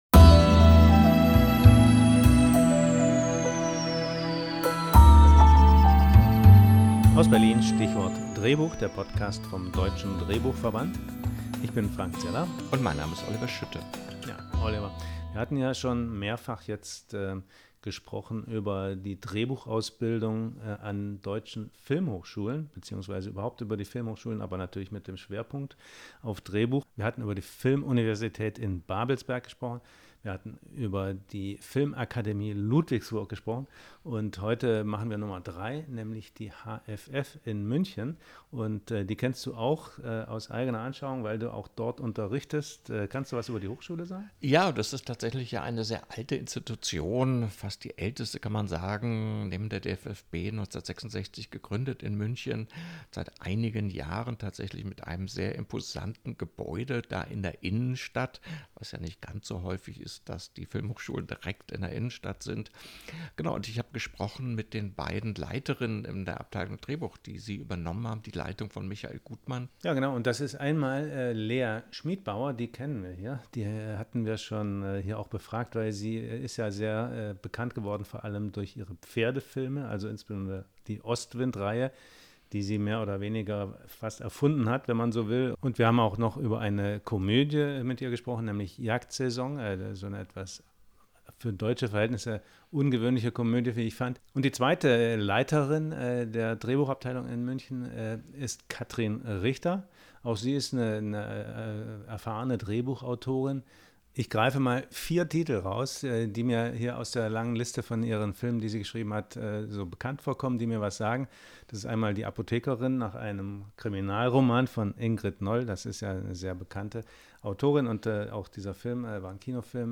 Im Gespräch geht es um den Spagat zwischen Kunsthochschule und Kaderschmiede, um die Frage, was Architektur mit Kreativität zu tun hat, und um eine junge Generation, die zwischen Realismus und Fantasy ihre eigene filmische Sprache sucht. Wir hören, warum viele Studierende heute direkt nach dem Abitur kommen, wie sie lernen, Spannung zu erzeugen, und welche Herausforderungen das Schreiben für Film und Fernsehen mit sich bringt.